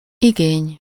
Ääntäminen
US : IPA : [ˈkleɪm]